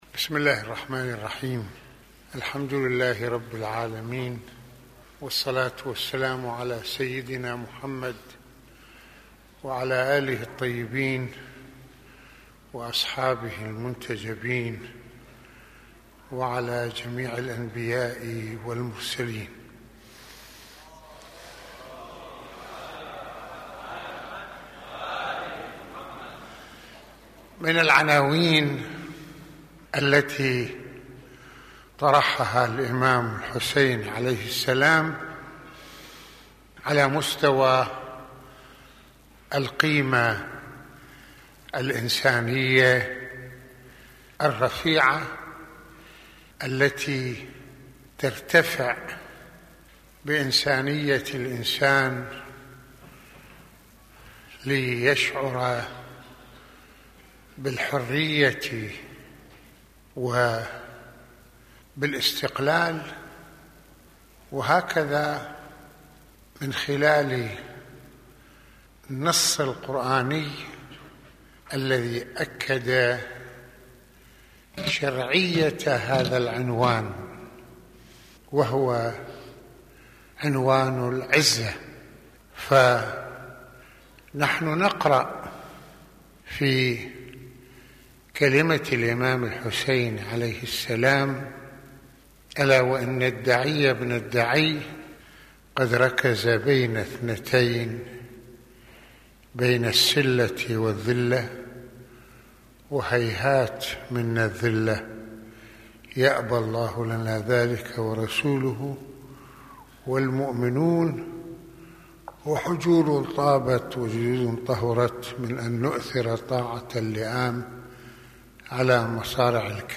- يتحدث سماحة المرجع السيد محمد حسين فضل الله (رض) في هذه المحاضرة العاشورائية عن العزة كقيمة إنسانية جسّدها الإمام الحسين (ع) بمواقفه وسلوكه ، فالله تعالى يرفض للإنسان أن يعيش الذل بل أراد له أن يكون عزيزاً به ، والإمام الحسين أراد أن يفتح عقول الناس وقلوبهم على الحق...